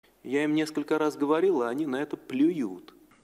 Звуки высказываний Медведева